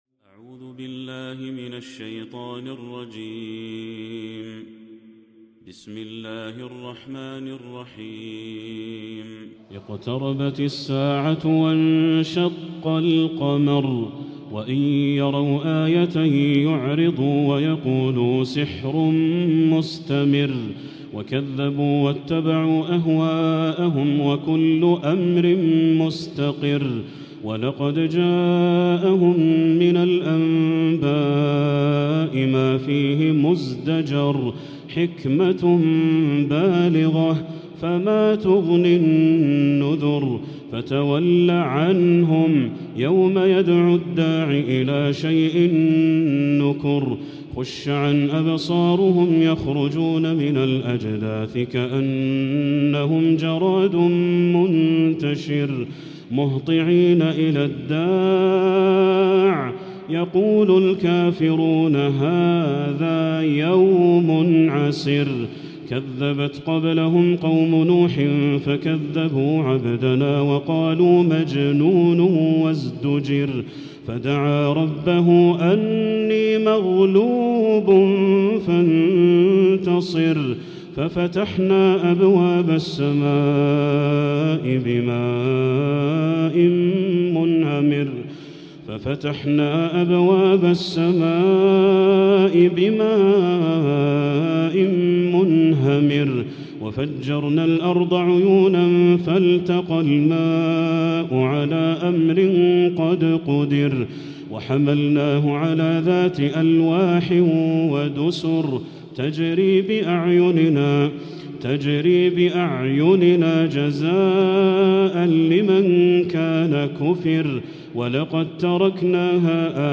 تلاوة في غاية العذوبة ! سورة القمر كاملة من المسجد الحرام للشيخ بدر التركي > السور المكتملة للشيخ بدر التركي من الحرم المكي 🕋 > السور المكتملة 🕋 > المزيد - تلاوات الحرمين